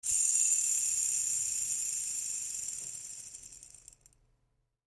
ブレーキ鳴き.mp3